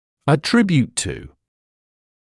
[ə’trɪbjuːt tuː][э’трибйуːт туː]относить к; относить за счёт